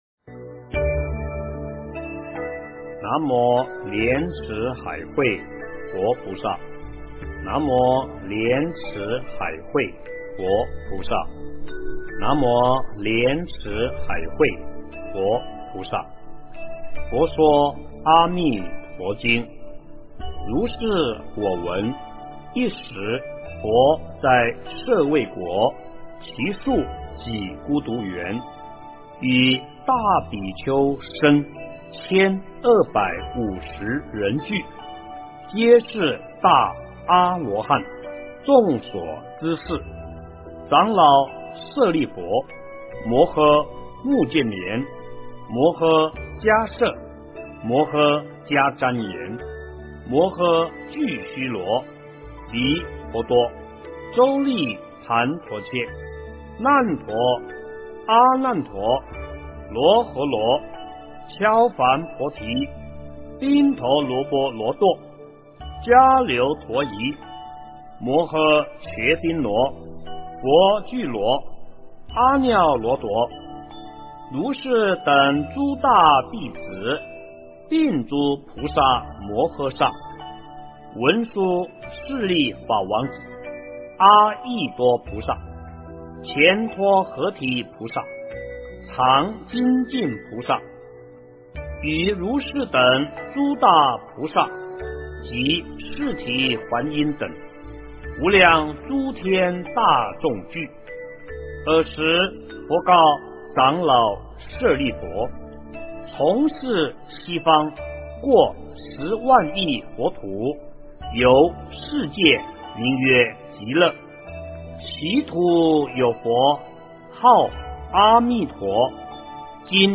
佛说阿弥陀经 - 诵经 - 云佛论坛